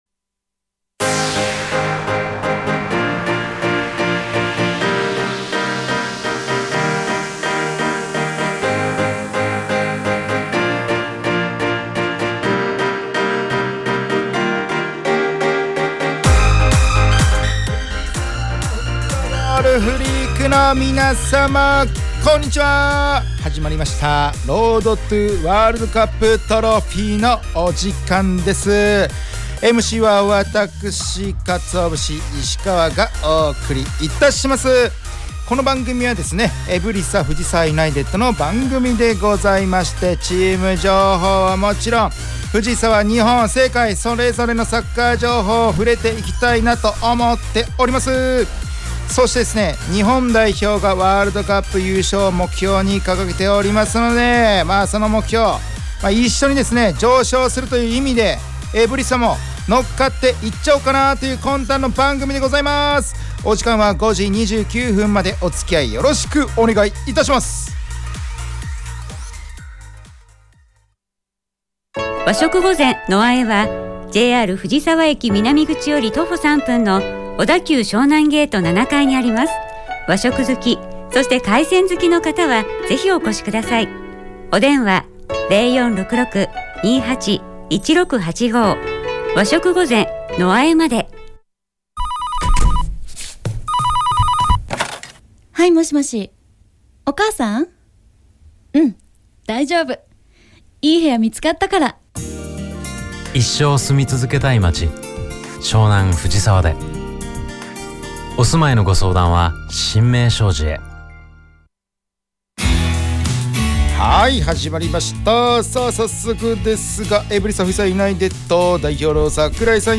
エブリサ藤沢ユナイテッドが提供する藤沢サッカー専門ラジオ番組『Road to WC Trophy』の第2期の第89回放送が12月12日(金)17時に行われました☆